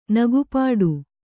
♪ nagu pāḍu